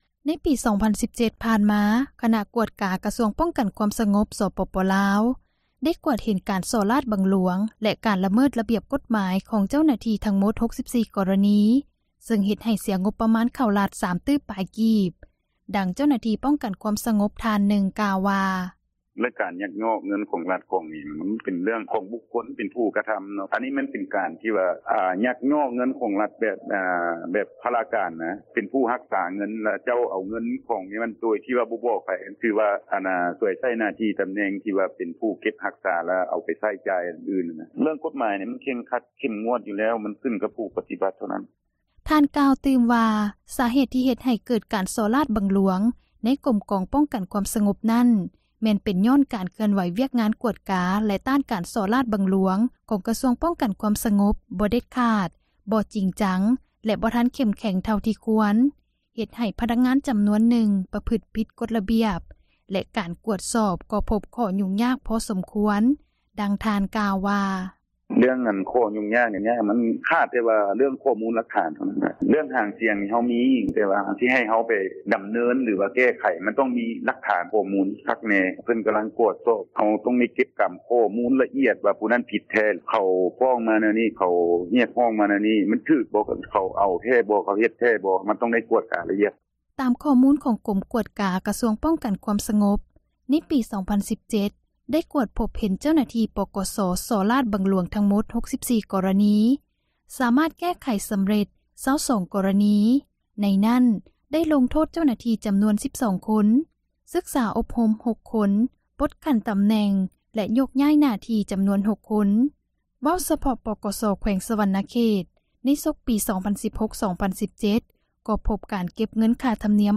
ໃນປີ 2017 ຜ່ານມາຄນະກວດກາກະຊວງປ້ອງກັນຄວາມສະຫງົບ ສປປລາວ ໄດ້ກວດເຫັນການສໍ້ຣາດບັງຫຼວງ ແລະການຣະເມີດຣະບຽບ ກົດໝາຍ ຂອງເຈົ້າໜ້າທີ່ທັງໝົດ 64 ກໍຣະນີ ເຊິ່ງເຮັດໃຫ້ເສັຍງົບປະມານເຂົ້າຣັຖ 3 ຕື້ປາຍກີບ, ດັ່ງ ເຈົ້າໜ້າທີ່ ປ້ອງກັນຄວາມສະຫງົບ ທ່ານນຶ່ງກ່າວວ່າ: